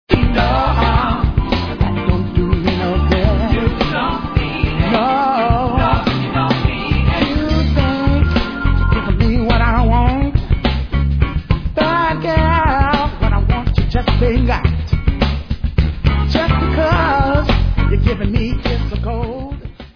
sledovat novinky v oddělení Jazz/Fusion